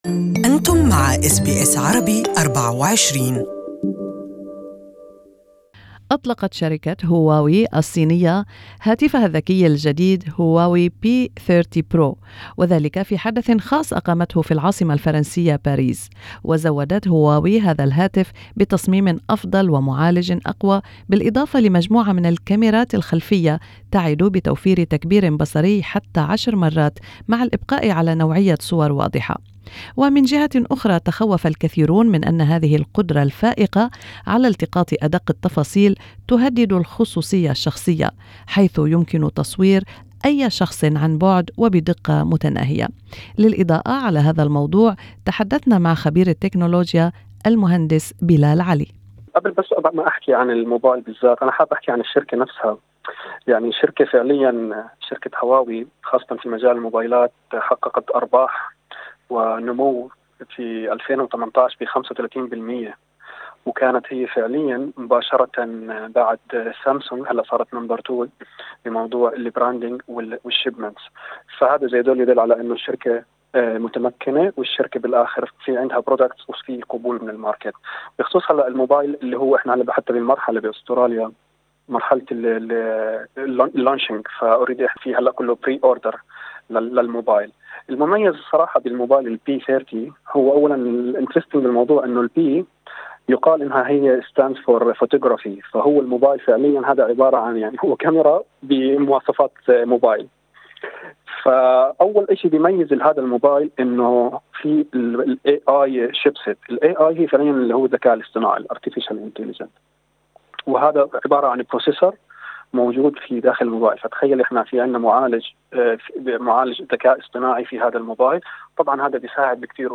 المزيد في اللقاء تحت الشريط الصوتي أعلاه.